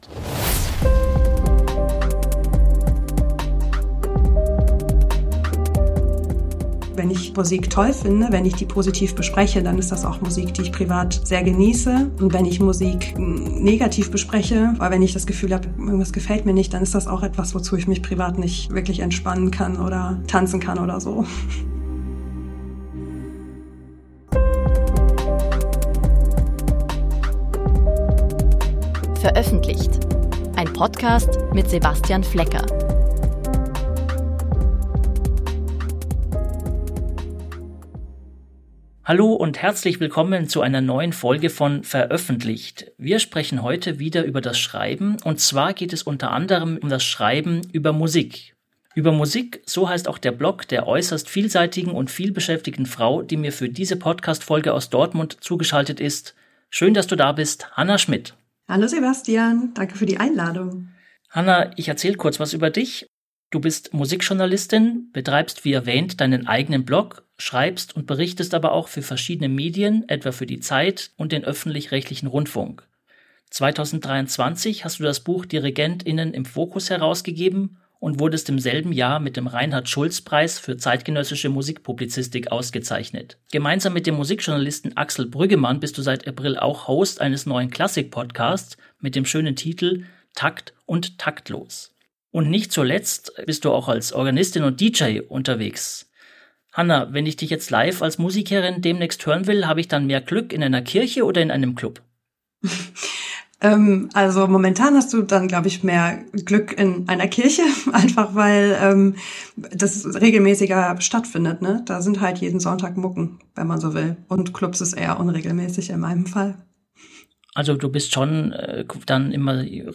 Musikerin, Journalistin, Autorin - ein Gespräch über Klangräume, Diskurs und Machtgefälle.